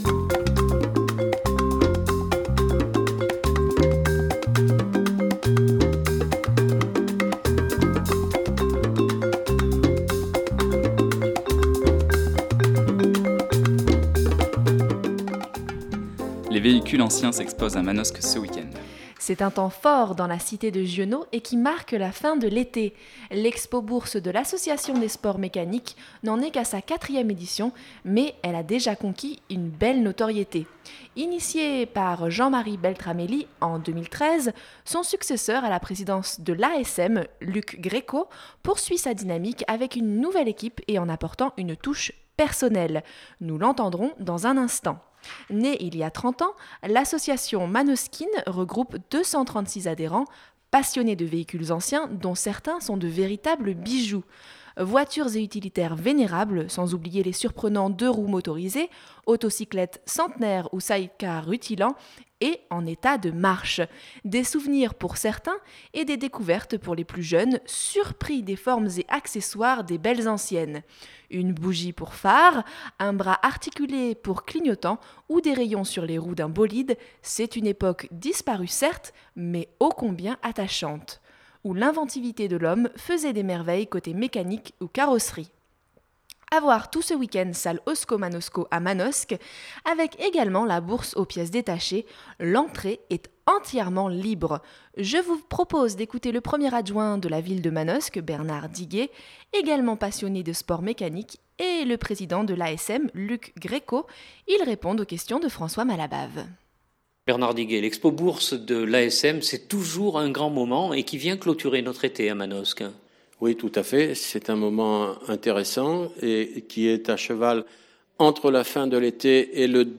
A voir tout ce week-end salle Osco Manosco à Manosque avec également la bourse aux pièces détachées ; l’entrée est entièrement libre. Je vous propose d’écouter le 1° adjoint de la Ville de Manosque Bernard Diguet